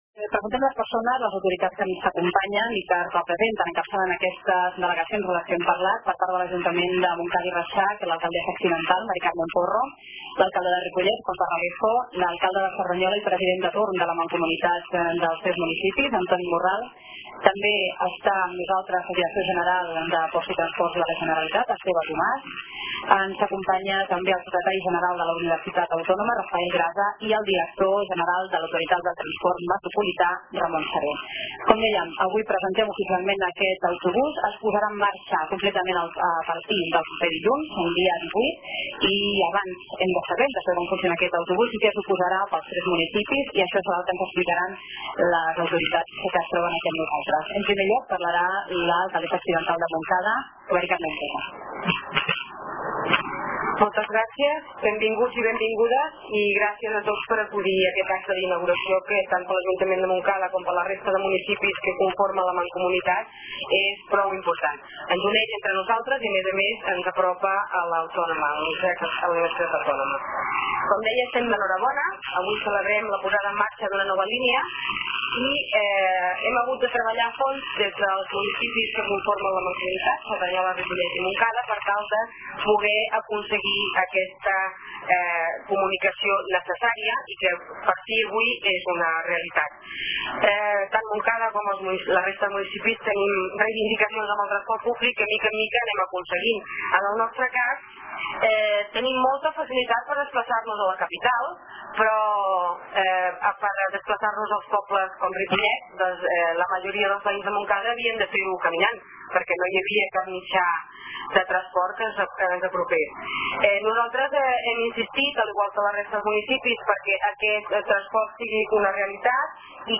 Descarregar arxiu ripollet-serveis-bus-648-inauguracio-150906.mp3
Escolteu els parlaments a l'arxiu d'�udio adjunt.